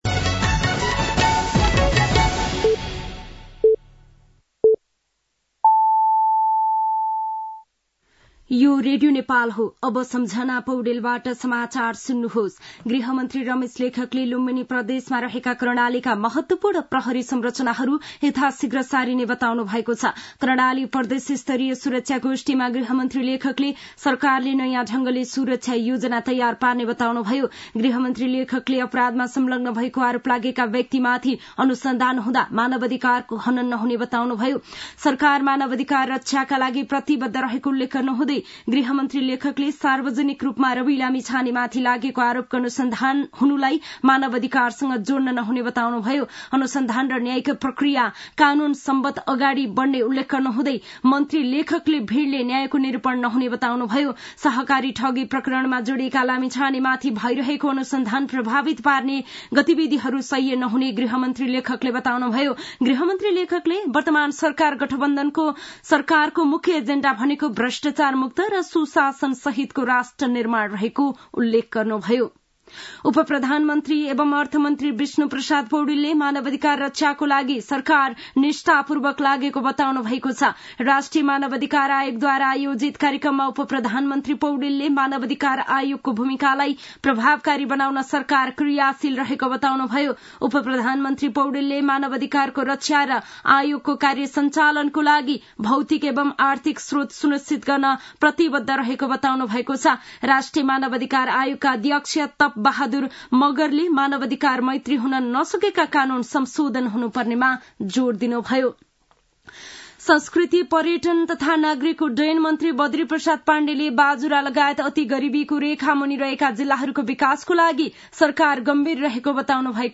दिउँसो ४ बजेको नेपाली समाचार : २६ मंसिर , २०८१
4-pm-Nepali-news-.mp3